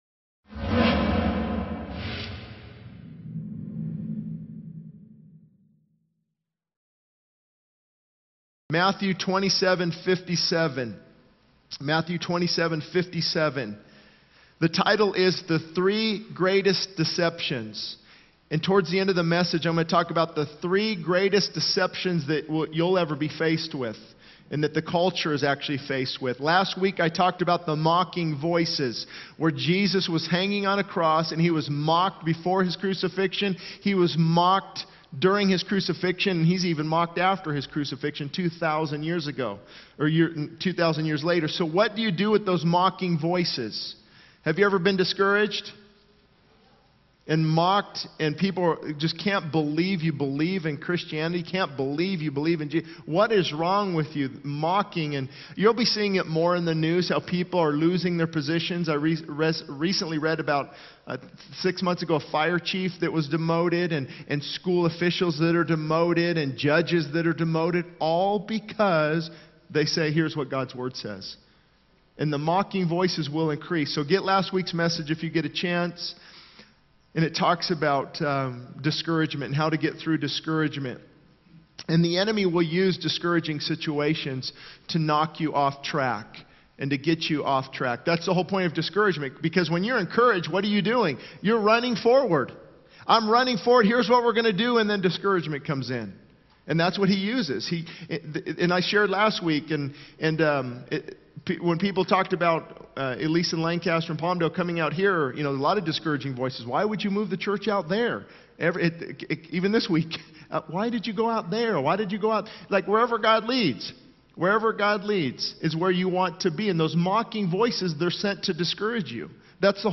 The sermon titled 'The Three Greatest Deceptions' delves into the challenges of facing mocking voices, discouragement, and cultural deceptions. It emphasizes the importance of not being discouraged by mocking voices and highlights the need to surrender to God's plan, even in small beginnings. The sermon also addresses the deceptions of atheism, disbelief in the resurrection, and worshiping false gods, urging listeners to seek the truth and believe in Jesus as the risen Savior.